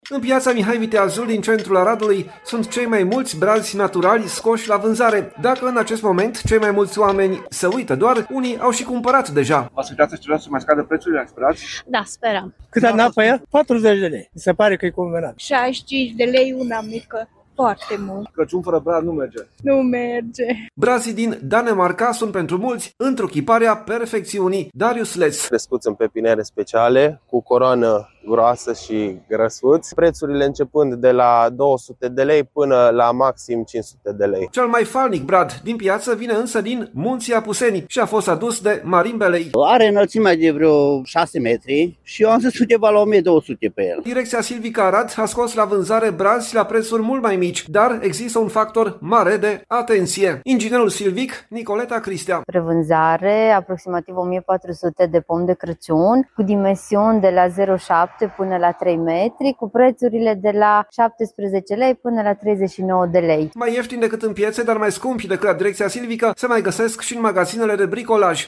„Cât am dat pe el? 40 de lei, mi se pare că e convenabil”, spune un bărbat.
„65 de lei unul mic, foarte mult”, consideră o femeie.